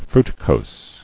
[fru·ti·cose]